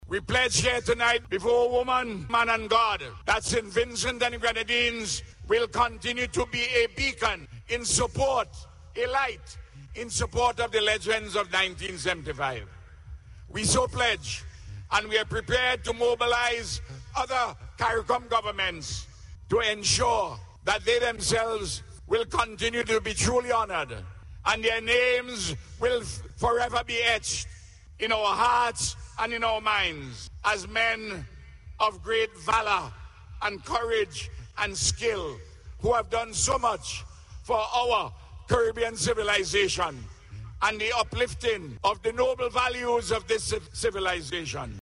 Speaking during the unveiling ceremony, Prime Minster Dr. Ralph Gonsalves also announced that Cabinet has granted citizenship to the Cricket Legends.